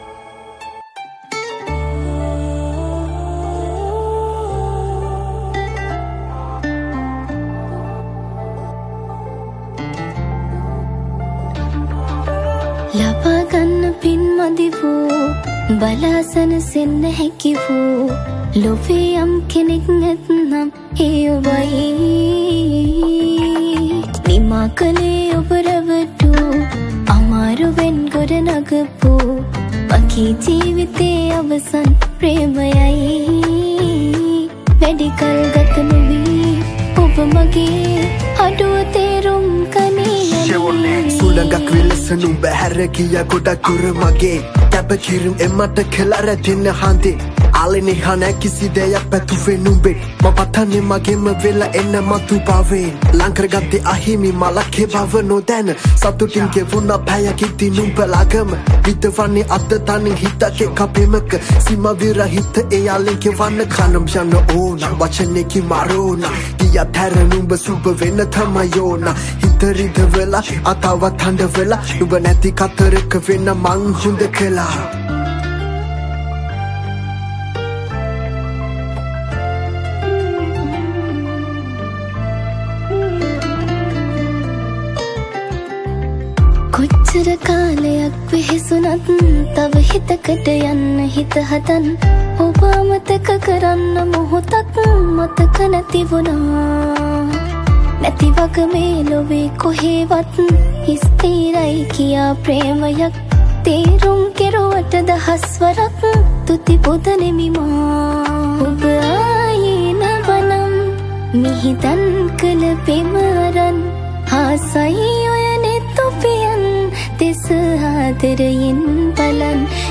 Releted Files Of Sinhala Mashup Songs